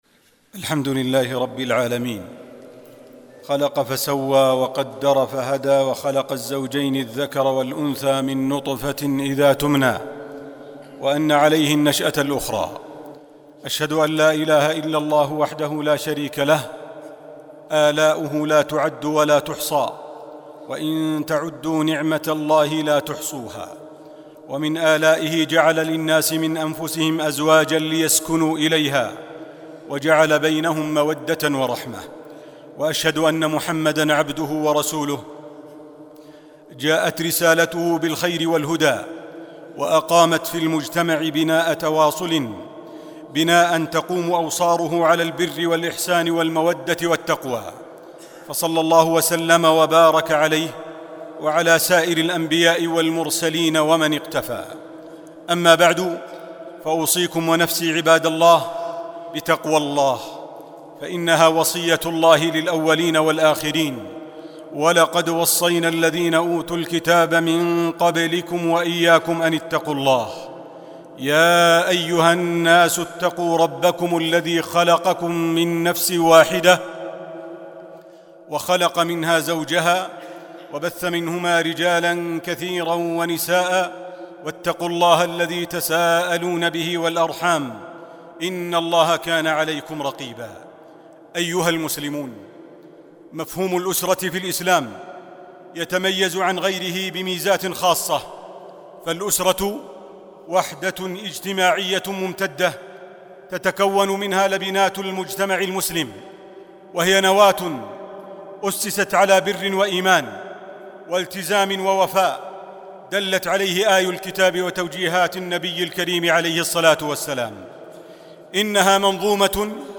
الخطب الصوتية